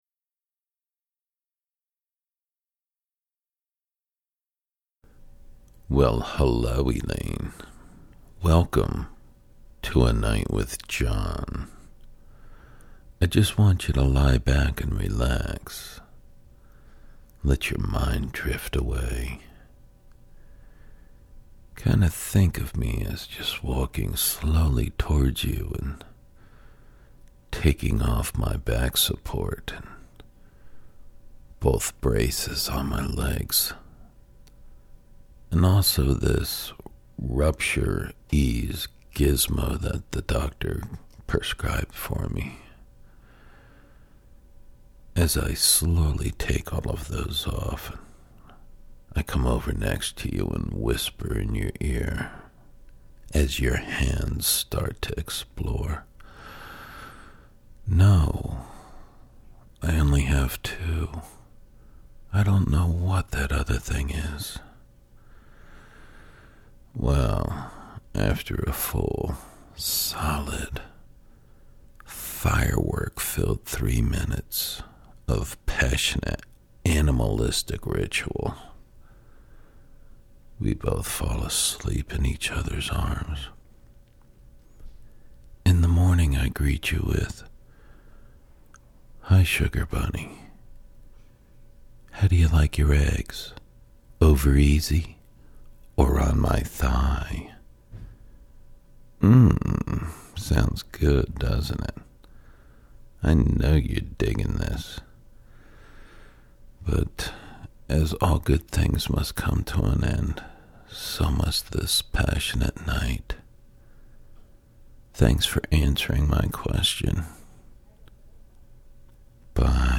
Deep english speaking voice, authorative, manly, believable, honest, raspy, rugged.
Sprechprobe: eLearning (Muttersprache):